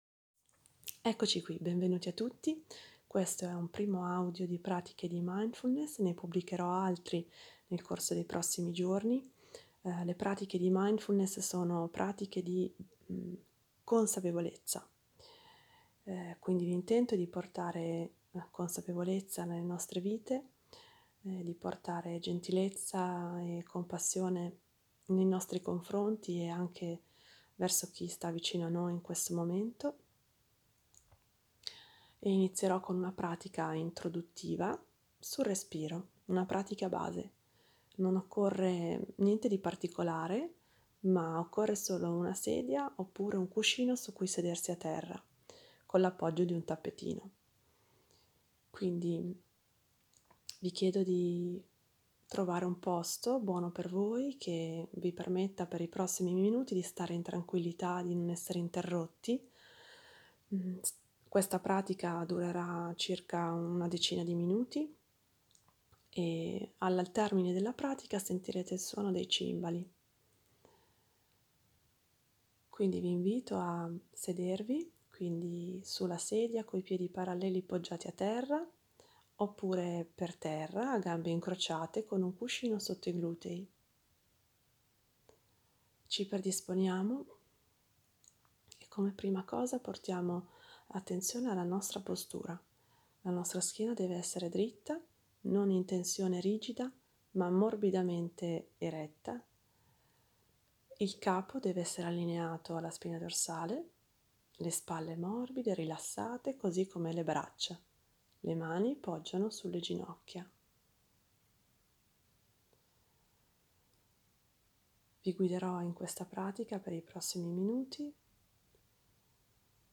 Pratica-Mindfulness-Aprile-2020.m4a